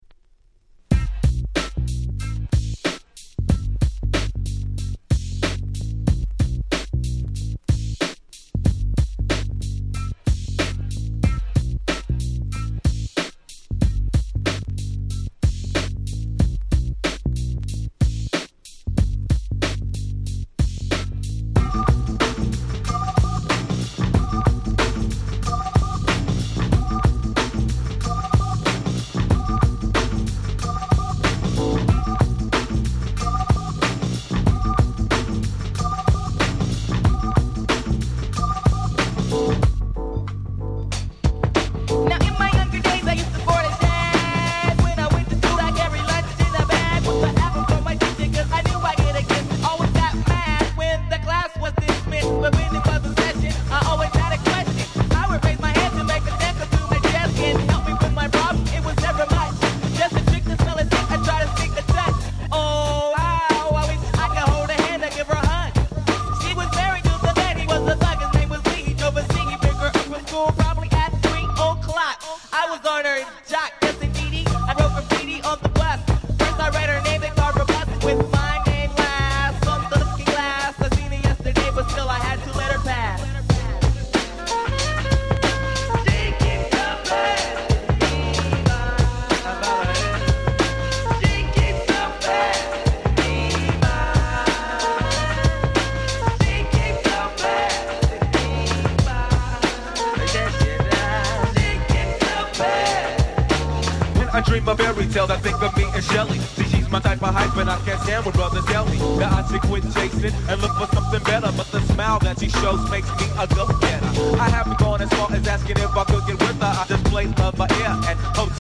ウィッキド ウィッキード ミックス物 90's R&B Hip Hop 勝手にリミックス 勝手にRemix